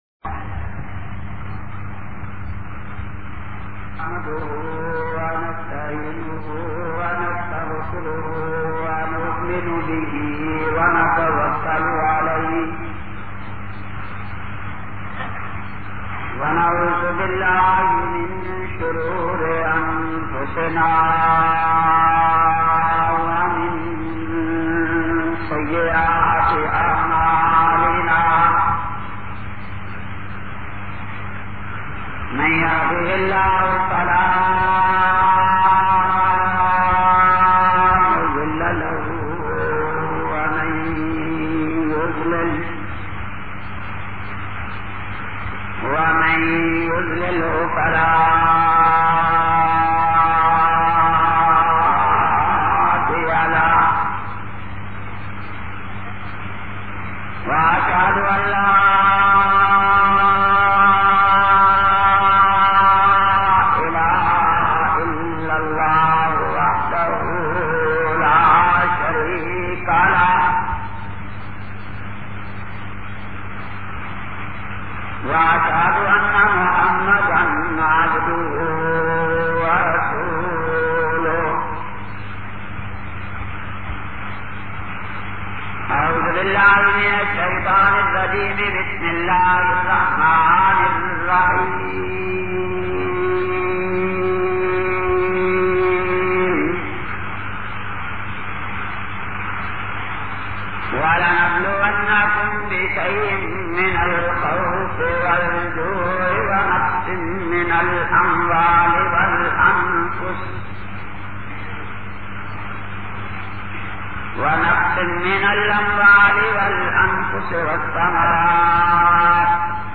116- Shahadat conference karachi.mp3